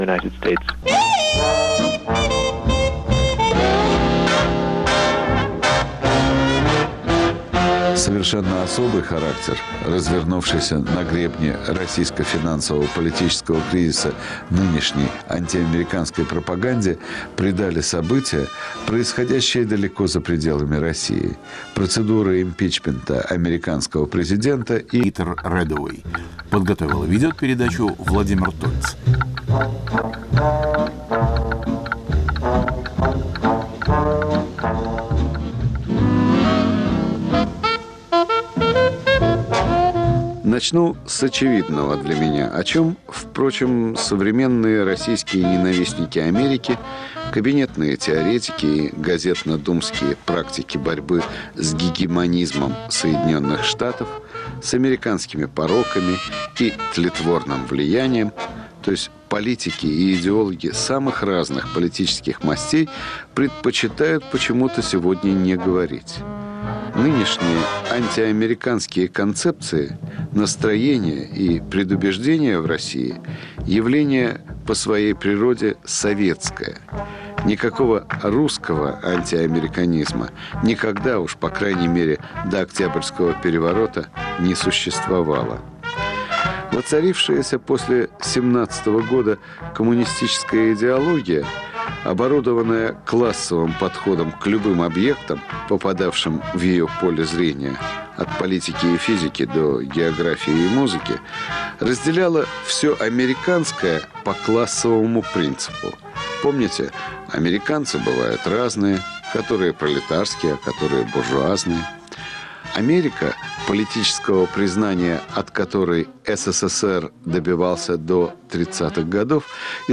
Холодная война, биполярный мир, антиамериканские мифы – все эти идеологические конструкции, корнями уходящие в эпоху сталинизма, вернулись в новейшее время. Америка, недолгое время побывшая в статусе идеала, снова превращается в жупел. О прошлом и настоящем советского антиамериканизма размышляют гости программы историк Александр Чубарьян и политолог Питер Реддауэй.